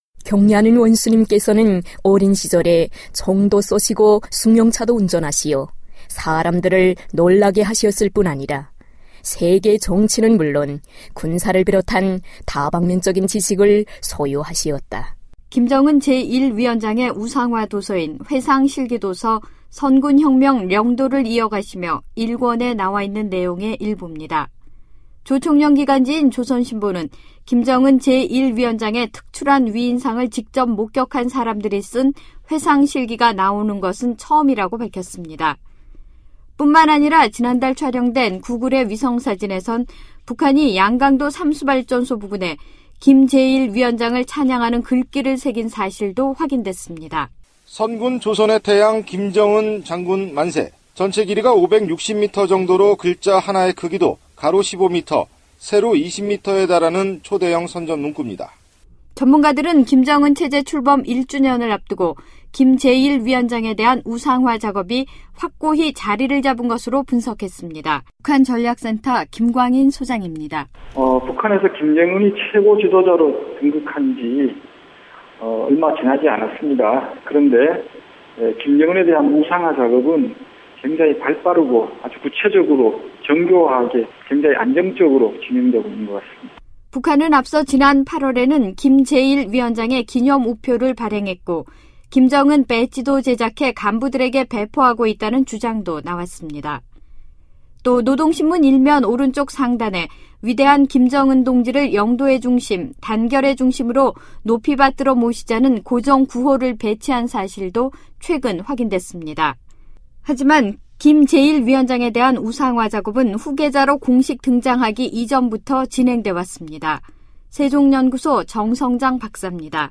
저희 VOA는 김정은 제1위원장의 우상화 과정을 짚어보는 주간 기획보도를 준비했습니다. 오늘은 첫 순서로 이미 북한 주민들 사이에 확고하게 자리를 잡은 우상화 과정을 취재했습니다.